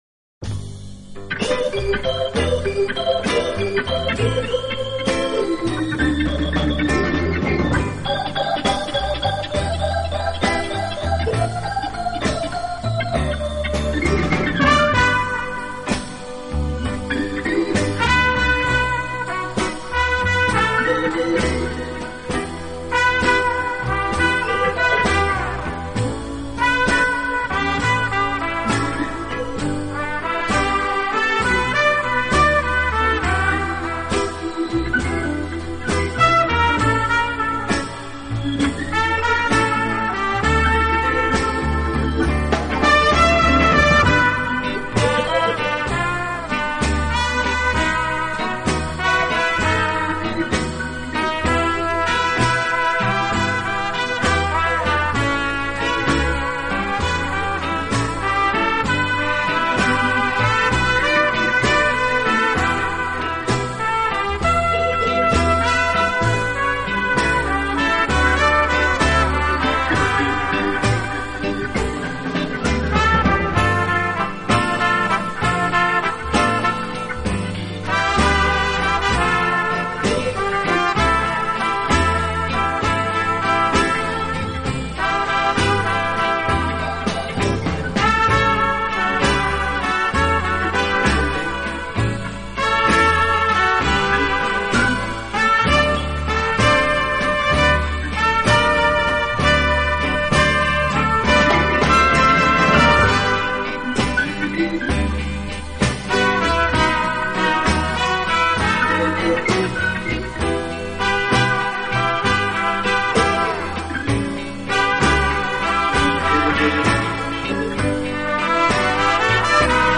【轻音乐】
【顶级轻音乐】